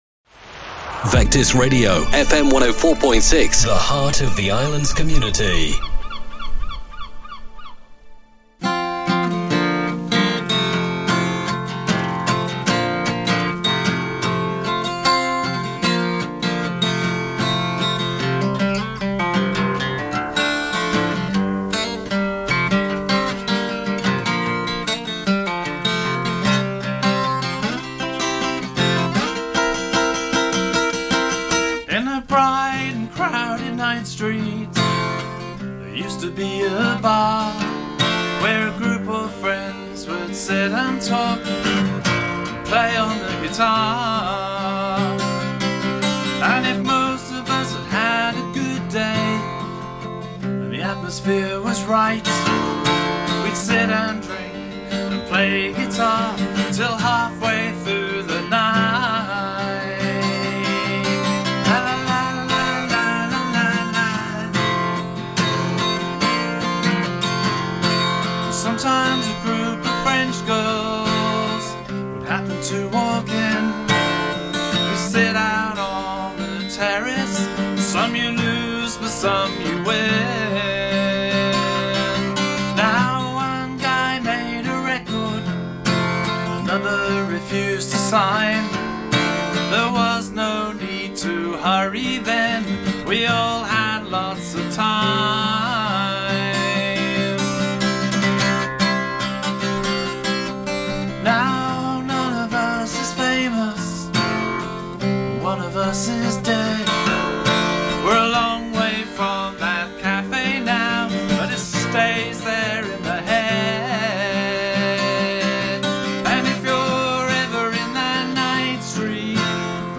If you missed the original broadcast here is the podcasted version of the chat